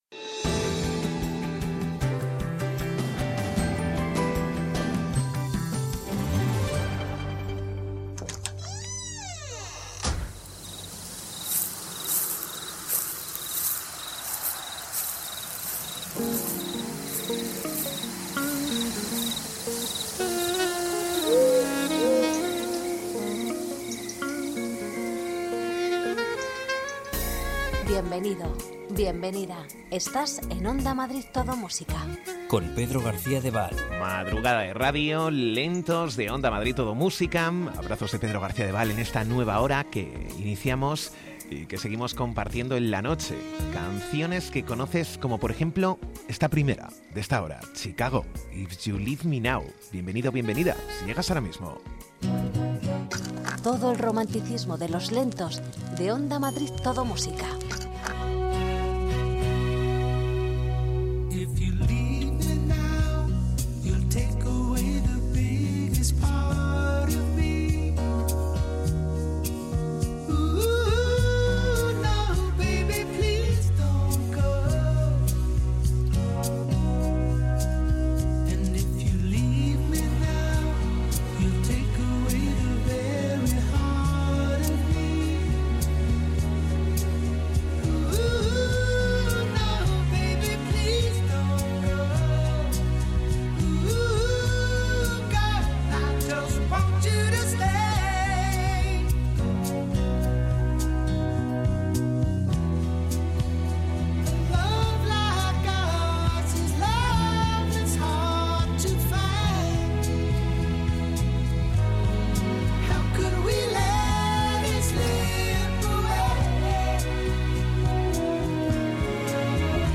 De lunes a viernes madrugadas de lentos